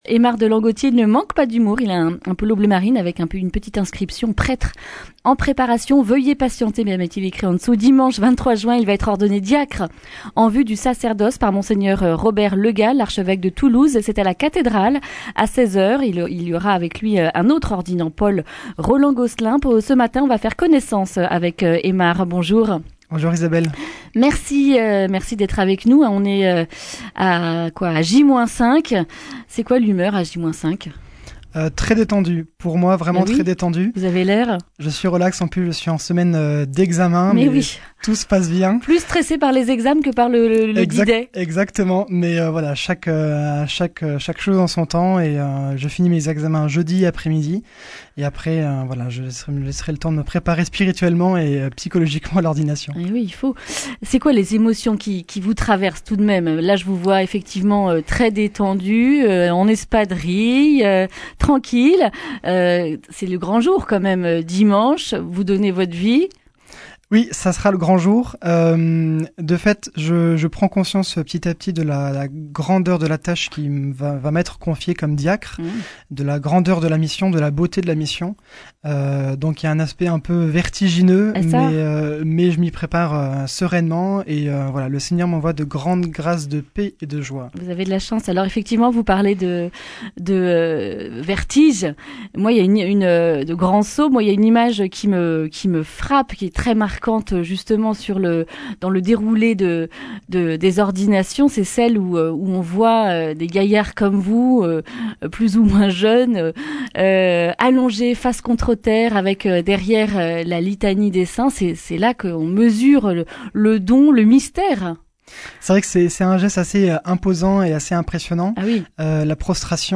A J-5, avec humour et décontraction, il nous dit comment le désir de donner sa vie à Dieu est né.
Accueil \ Emissions \ Information \ Régionale \ Le grand entretien \ Ordinations : « Je souhaite amener mes futurs paroissiens au Christ, et (…)